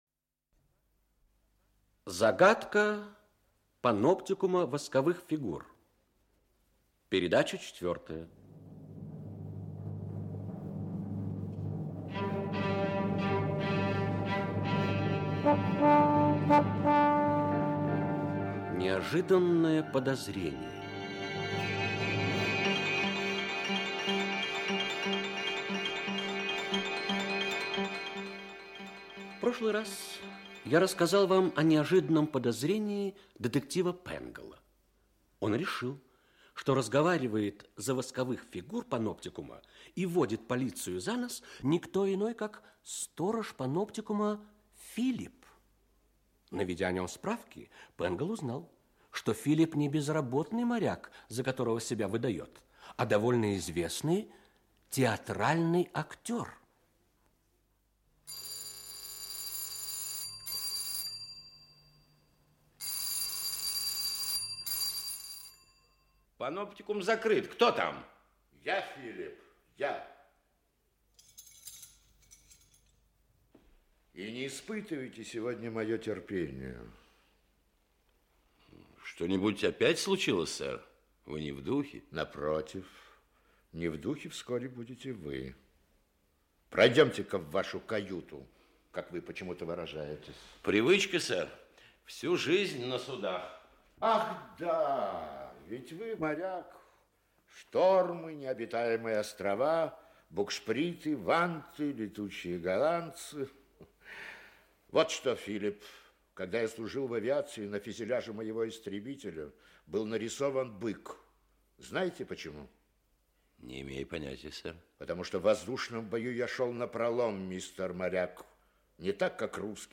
Аудиокнига Загадка паноптикума восковых фигур. Часть 4. Неожиданное подозрение | Библиотека аудиокниг